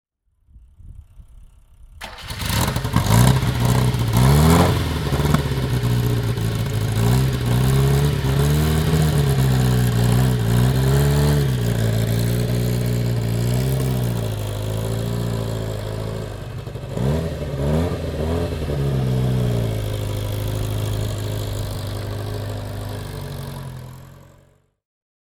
Abarth 1300 OT (1966) - Starten